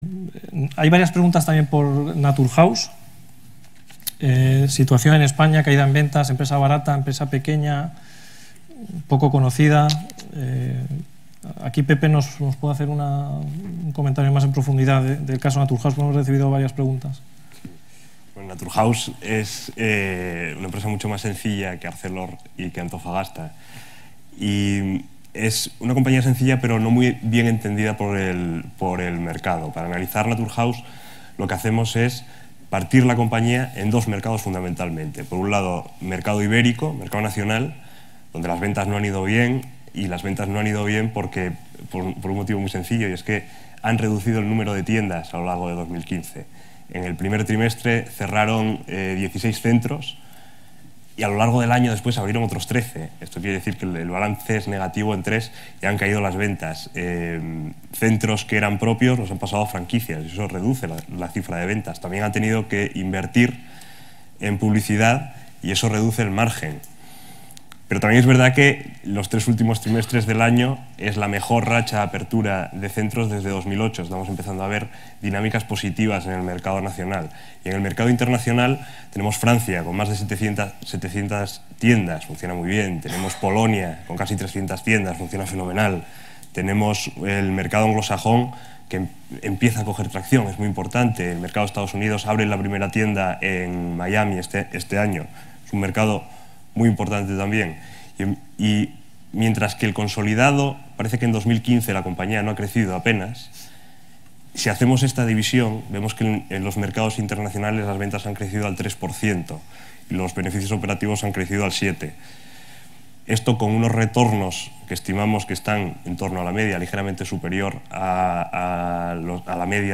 Estos opinaban hace unos meses (en el turno de preguntas de su presentación a inversores) sobre la empresa NATURHOUSE del siguiente modo: